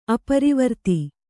♪ aparivarti